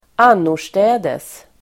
Ladda ner uttalet
annorstädes adverb (ålderdomligt), elsewhere [archaic]Uttal: [²'an:or_stä:des] Definition: på annan plats (in another place)